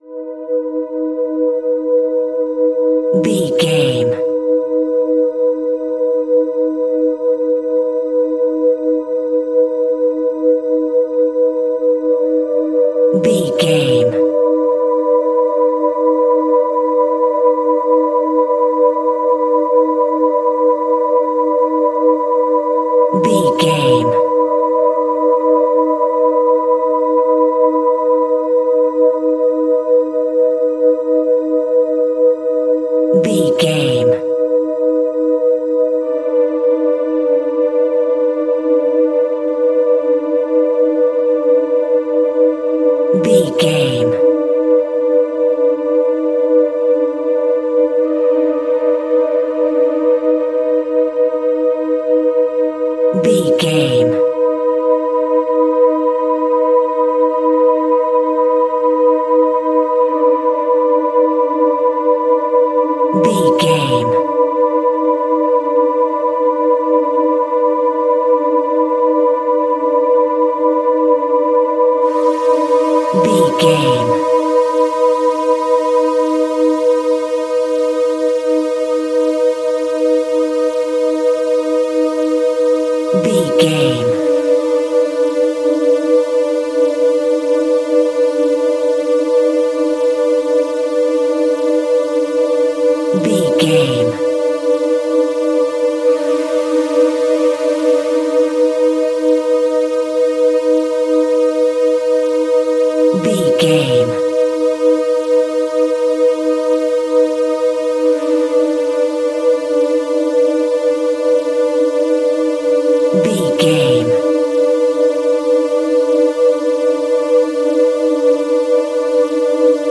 Survival horror
Aeolian/Minor
B♭
ominous
suspense
eerie
synthesizer
creepy
Synth Pads
atmospheres